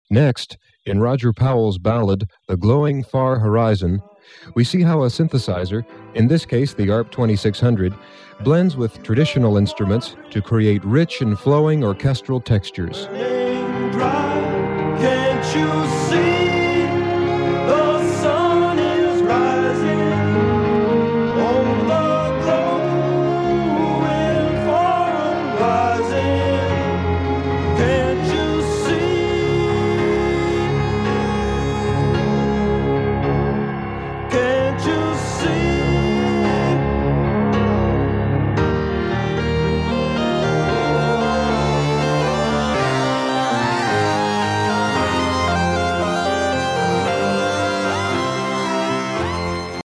ARP 2600